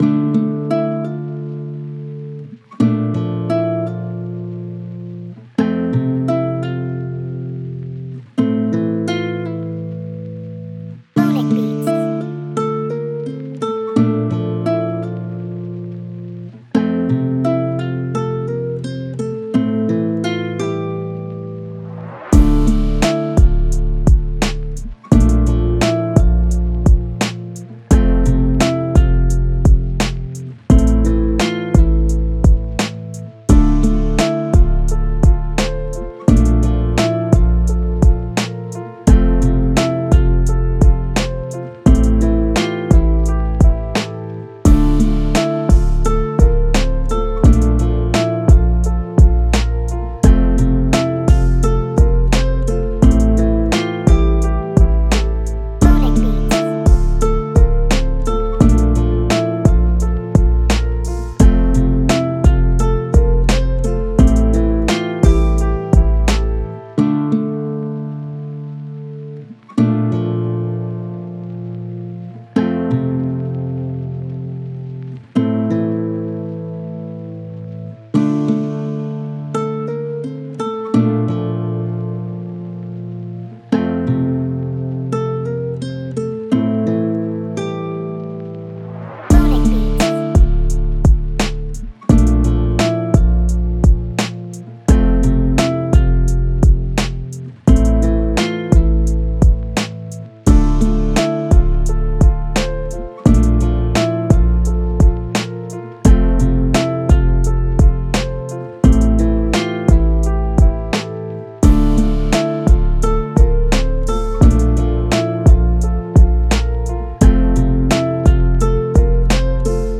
[HipHop Beat] "Lost"